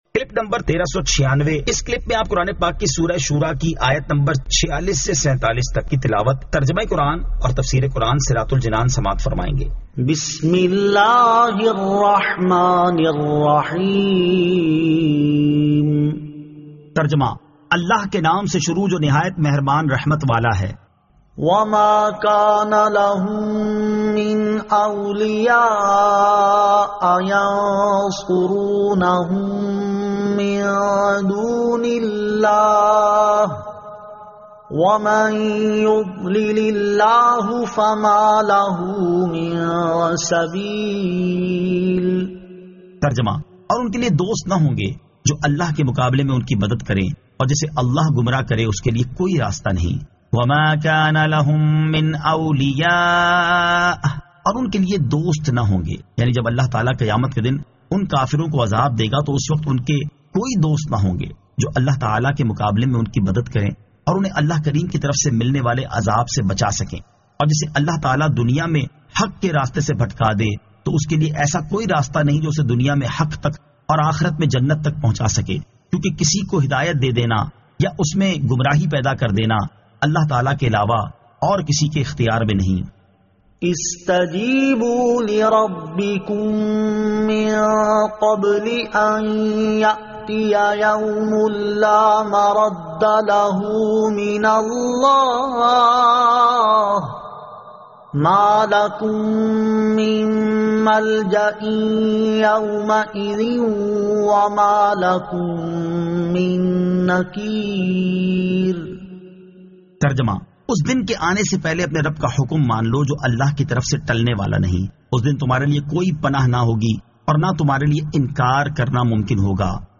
Surah Ash-Shuraa 46 To 47 Tilawat , Tarjama , Tafseer